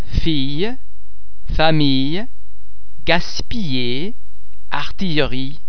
Please be mindful of the fact that all the French sounds are produced with greater facial, throat and other phonatory muscle tension than any English sound.
The French [ yeah ] sound is very much like the /y/ sound in the English words yes or eye.
·lle
ille_fille.mp3